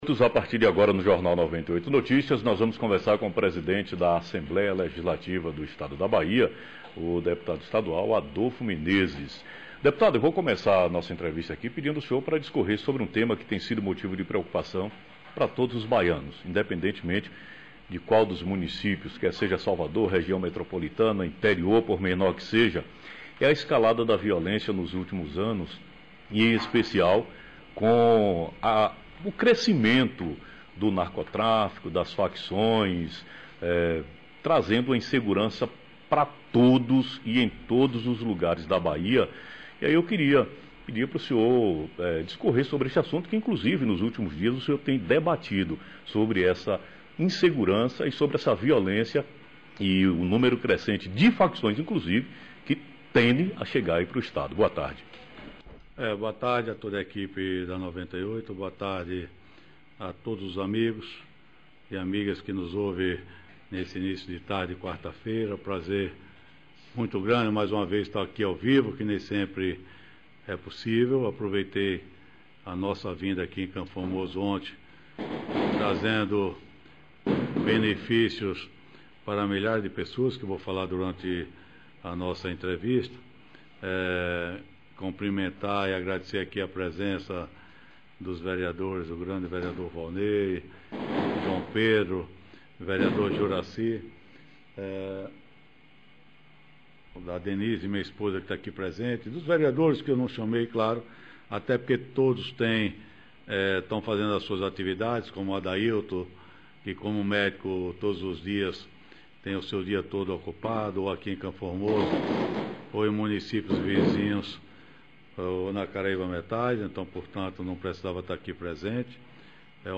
Entrevista ao vivo com o deputado estadual Adolfo Menezes presidente a ALBA falando sobre o mutirão de audiências